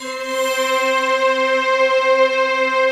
SI1 CHIME0CL.wav